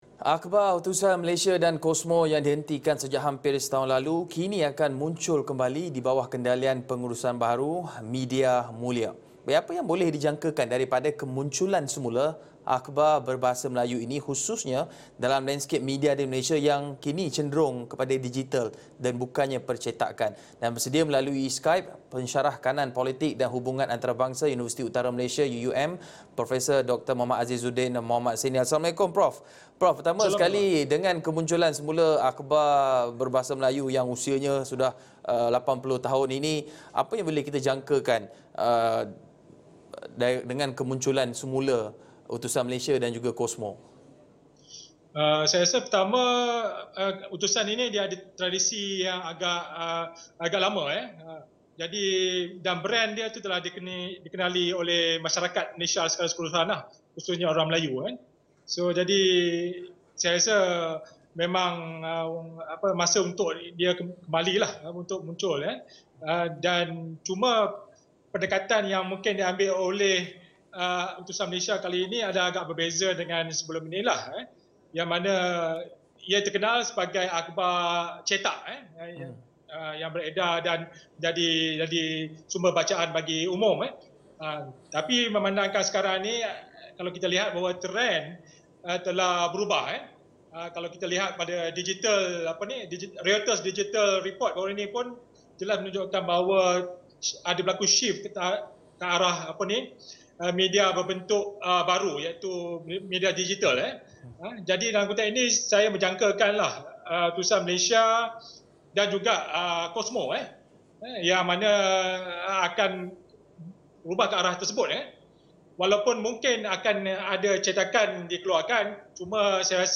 Bersedia melalui Skype